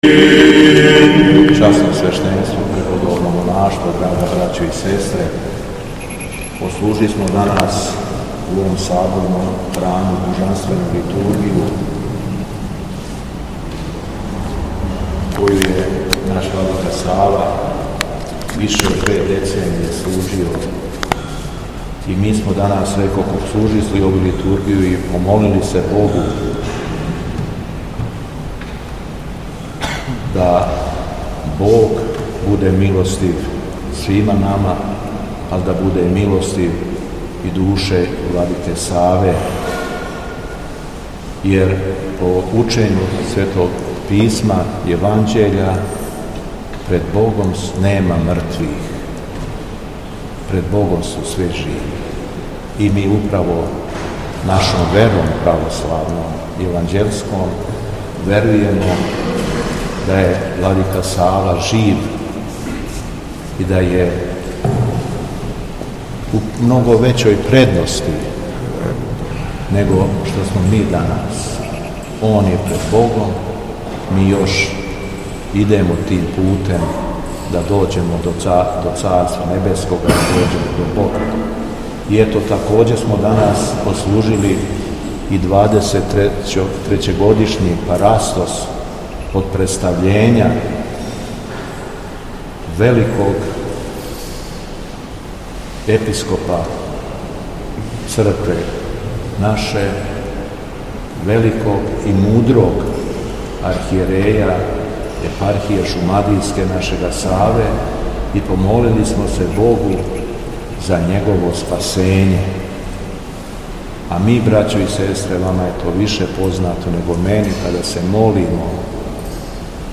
Духовна поука Његовог Високопреосвештенства Архиепископа крагујевачког и Митрополита шумадијског г. Јована